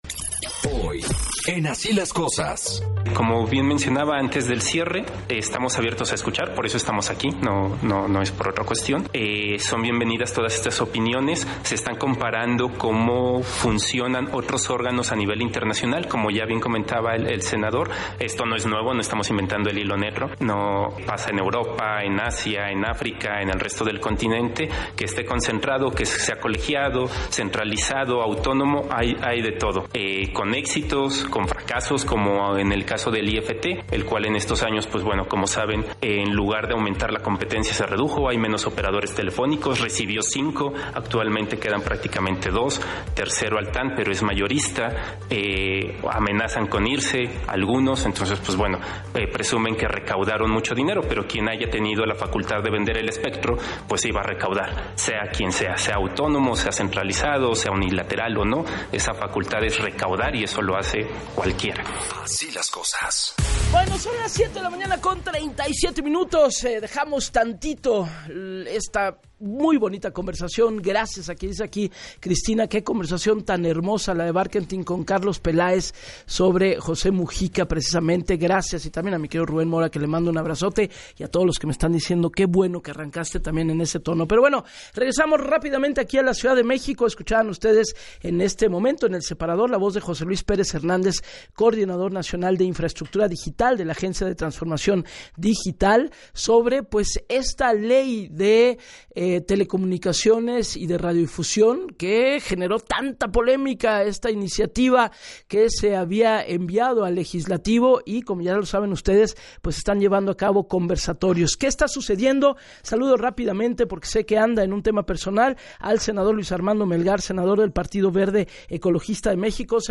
En entrevista para “Así las Cosas” con Gabriela Warkentin, puntualizó que no es un parlamento en el que se discute el dictamen, sino un conversatorio, ya que el primero es el debate entre academia, industria y sociedad para entender de manera amplia lo que opinan en su conjunto y es previo a su aprobación en comisiones.